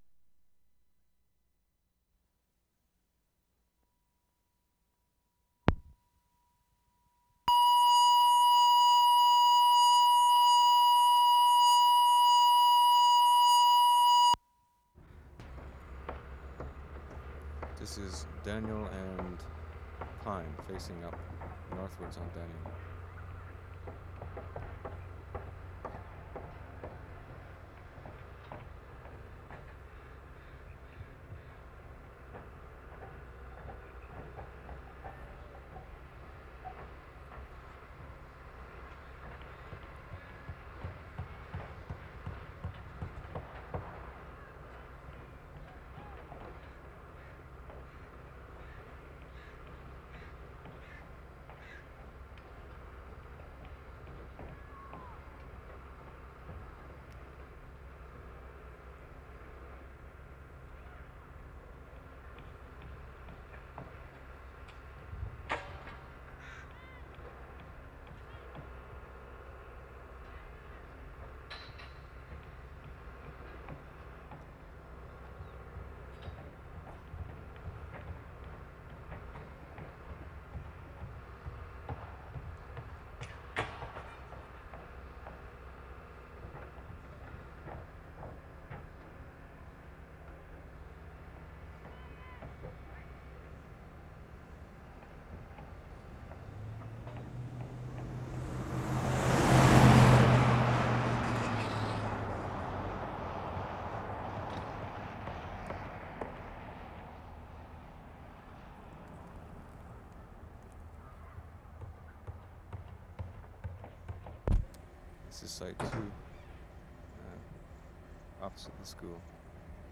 WORLD SOUNDSCAPE PROJECT TAPE LIBRARY
CHEMAINUS, BC November 1, 1976
Daniel and Pine: There is some hammering in the beginning then there is a group of children playing soccer. This is in a reverberant space. Sounds nice but odd.